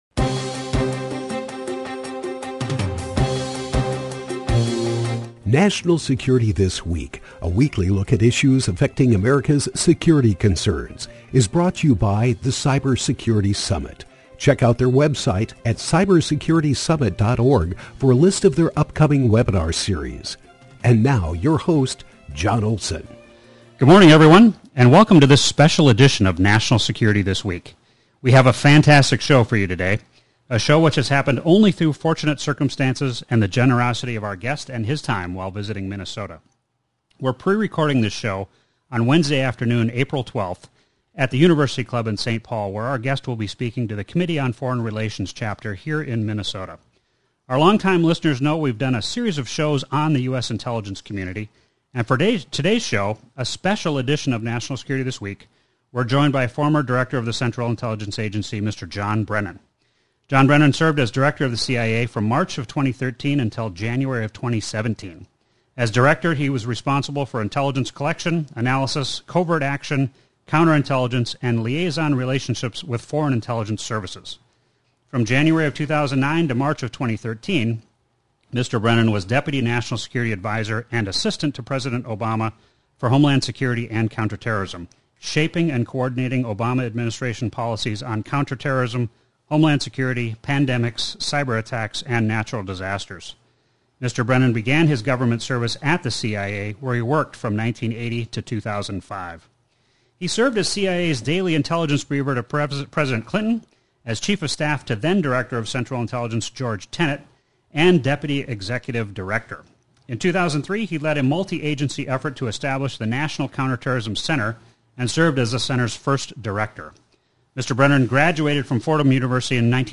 National Security This Week with former CIA Director John Brennan, 4-13-23 – KYMN Radio · Northfield, MN · AM 1080 & FM 95.1